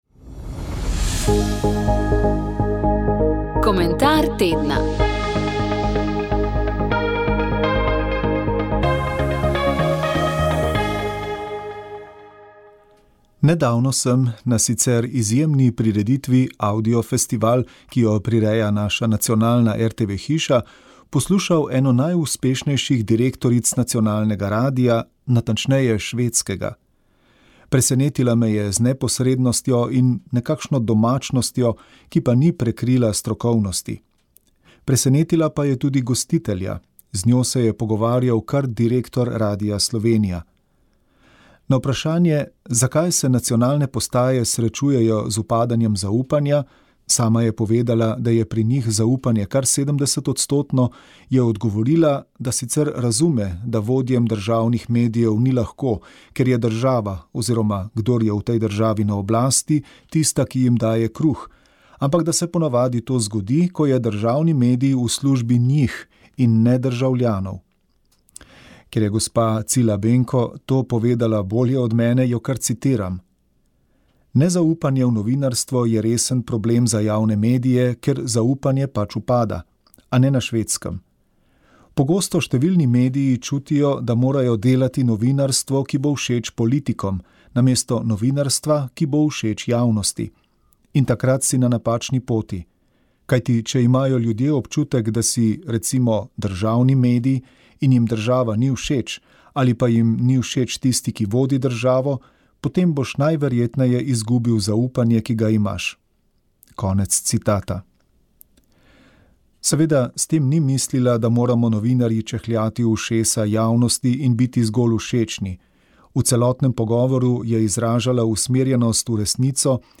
Slovenska matica in Študijski center za narodno spravo sta v ponedeljek, 18. novembra 2024, v Ljubljani pripravila znanstveni posvet Dachavski procesi – 75 let pozneje.